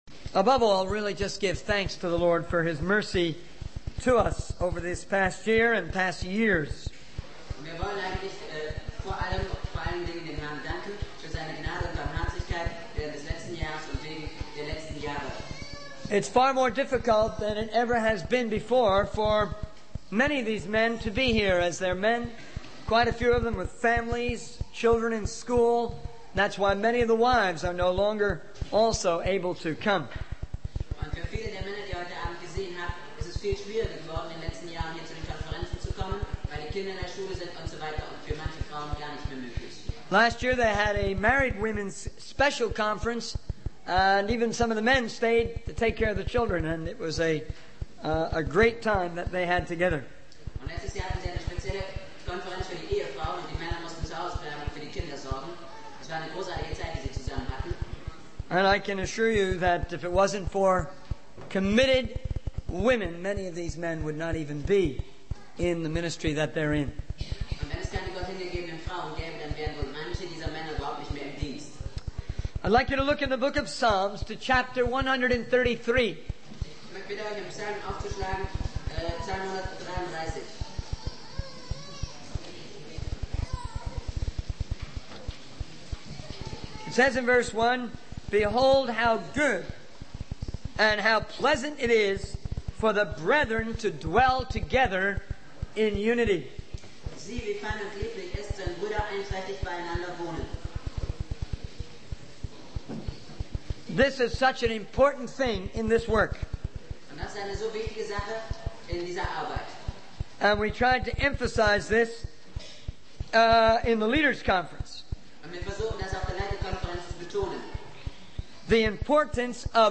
In this sermon, the speaker addresses the indifference and lack of vision for world missions, as well as the unbelief and materialism that hinder it. He emphasizes the importance of unity and fellowship among believers, highlighting the relationships formed during a leaders conference.